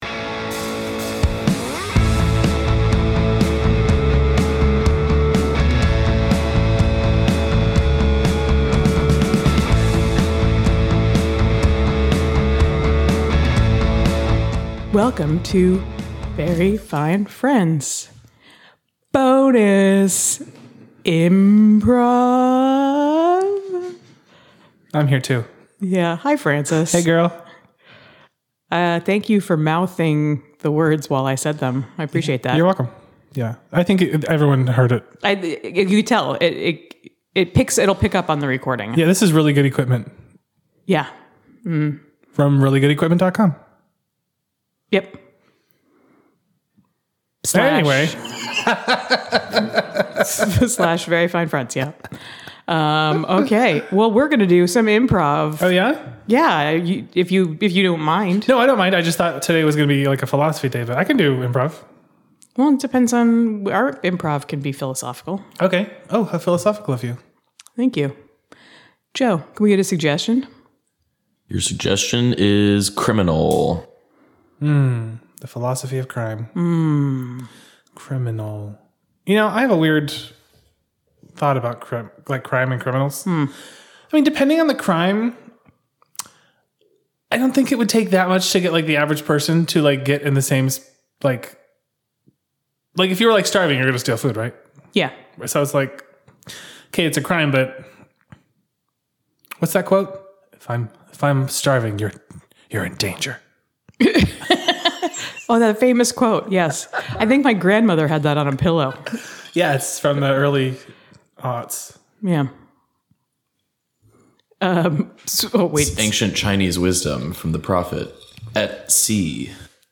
Bonus IMPROV - That's a Good Afternoon for Everybody